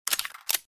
手枪格洛克换弹卸载弹夹.mp3